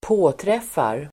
Uttal: [²p'å:tref:ar]